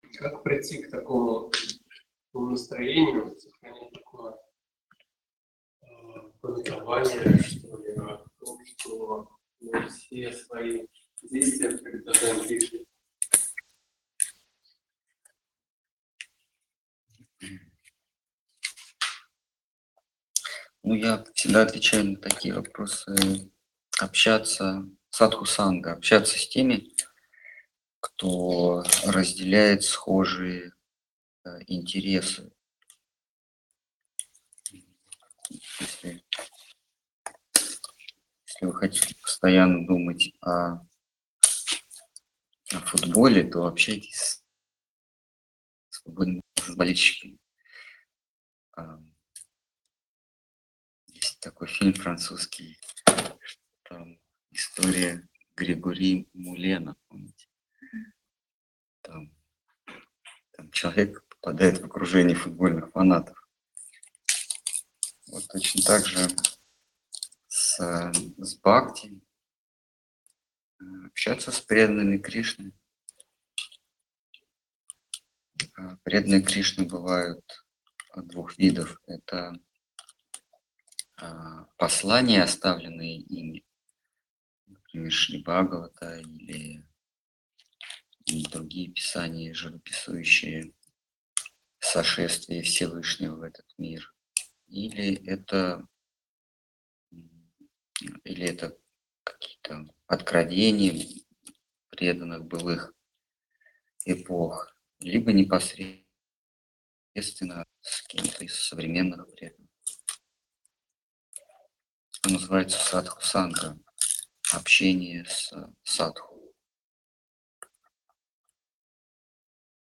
Ответы на вопросы из трансляции в телеграм канале «Колесница Джаганнатха». Тема трансляции: Слово Хранителя Преданности.